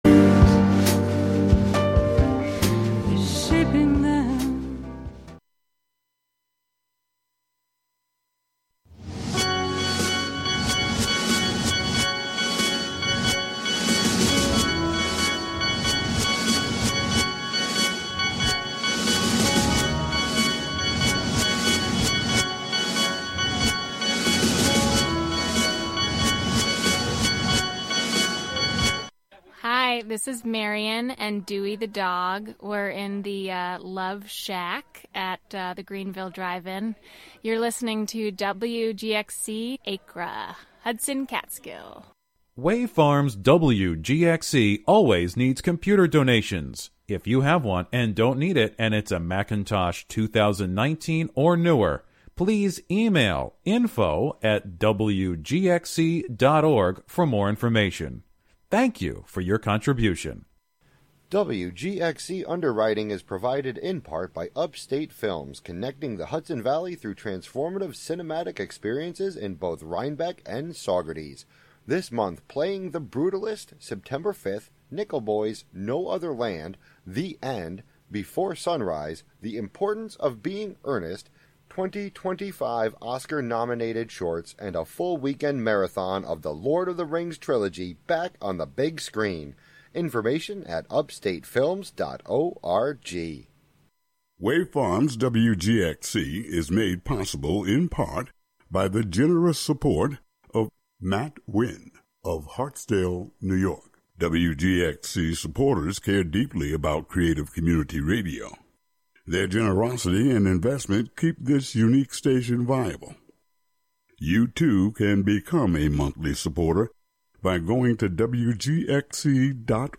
Music, talk, and schtick, just like any variety show. One difference, though, is the Democratic Socialists also confront power.